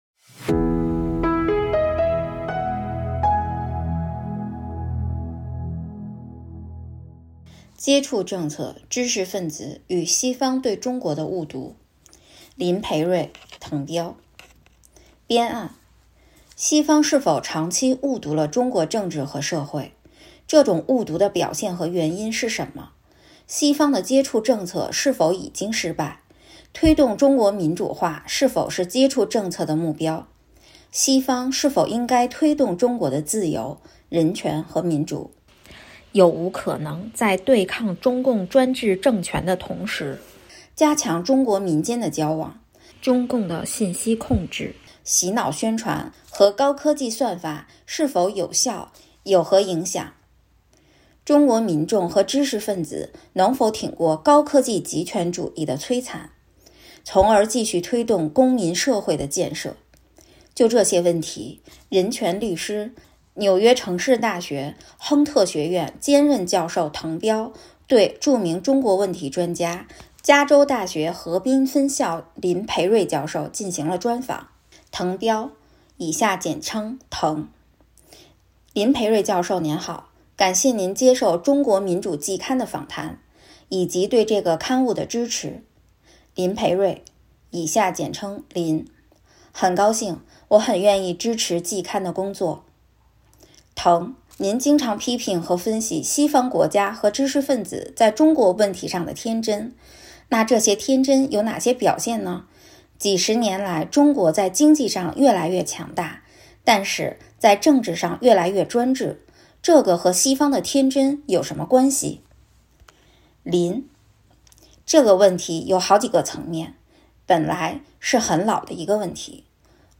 就这些问题，人权律师、纽约城市大学亨特学院兼任教授滕彪对著名中国问题专家、加州大学河滨分校林培瑞教授进行了专访。